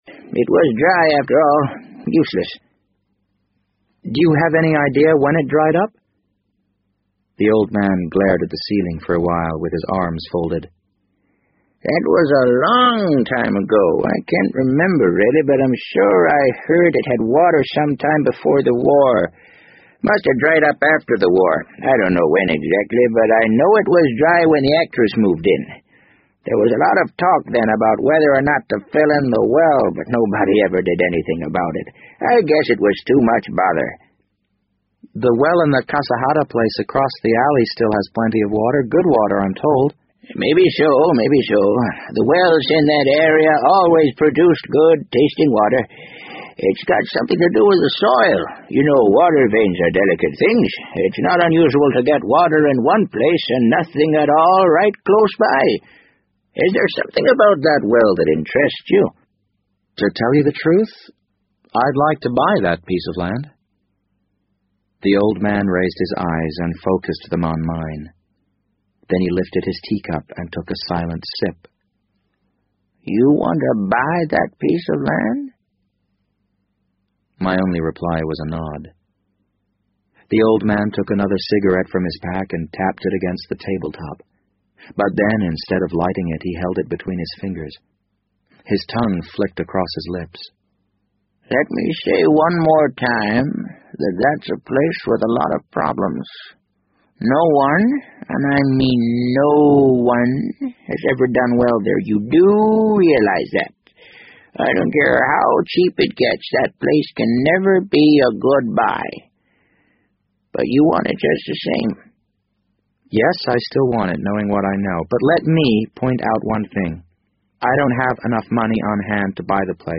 BBC英文广播剧在线听 The Wind Up Bird 009 - 10 听力文件下载—在线英语听力室